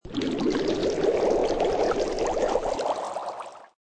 Звуки лопающихся пузырьков
Звук лопающихся булькающих пузырей